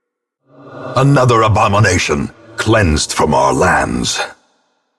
enemy-death-another-abomination.mp3